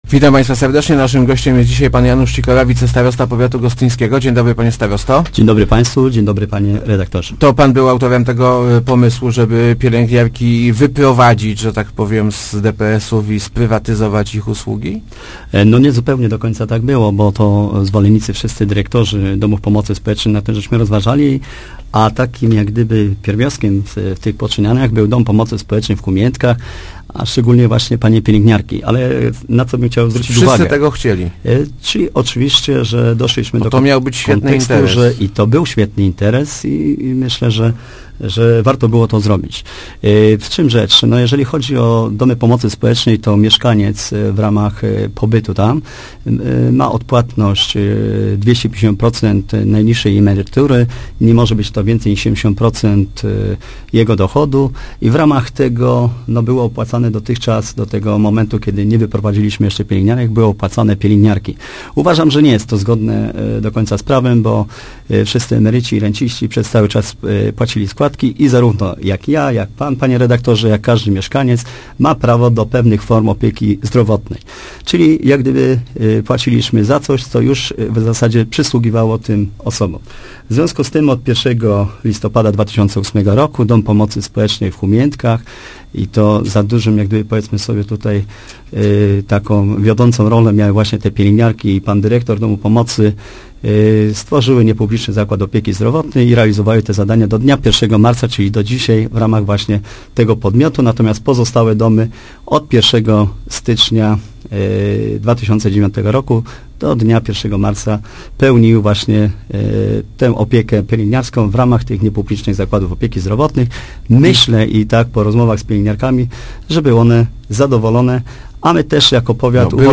Podopieczni Domów Pomocy Spo�ecznej b�d� nadal mieli opiek� piel�gniarsk� – zapewni� w Rozmowach Elki Janusz Sikora, wicestarosta gosty�ski. Najprawdopodobniej pe�ni� j� b�d� te same piel�gniarki co dot�d, mimo �e NFZ nie podpisa� z nimi kontraktu. Panie maj� by� zatrudnione przez nowych us�ugodawców.